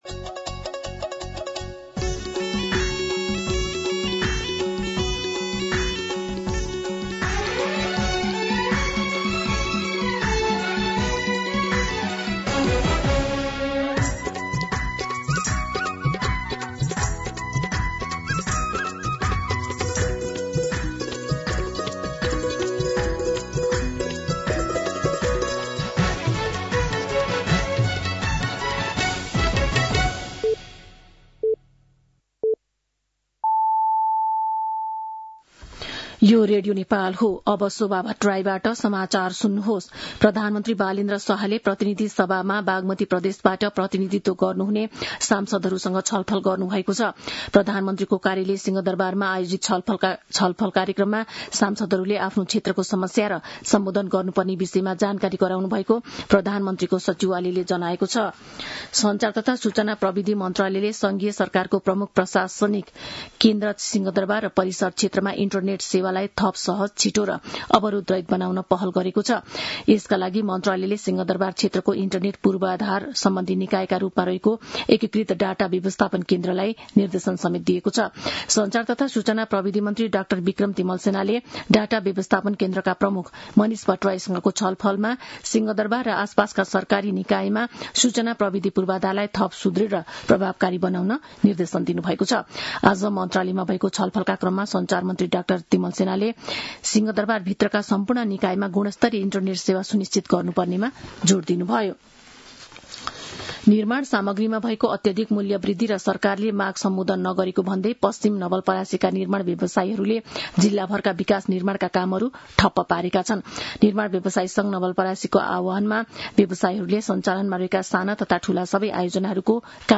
साँझ ५ बजेको नेपाली समाचार : ३० चैत , २०८२
5-pm-news-12-30.mp3